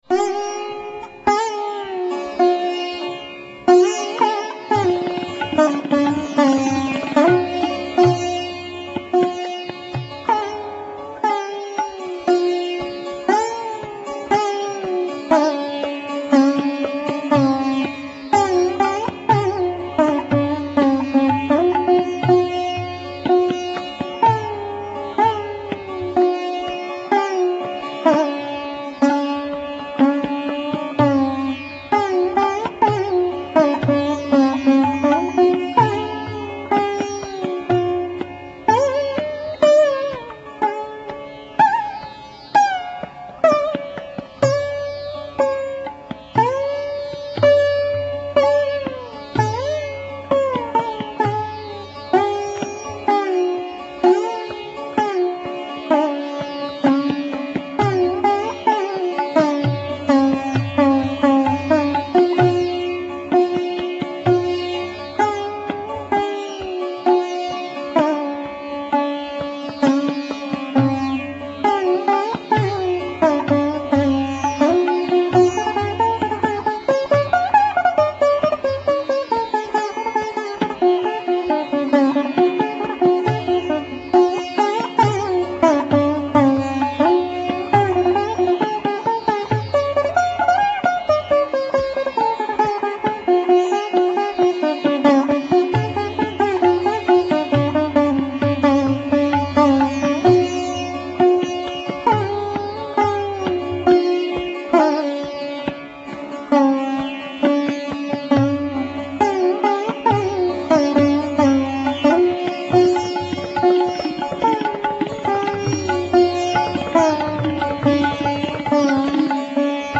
The Jait component is even more subtle in the rendition by
The gat is in madhya laya Ada Chautala.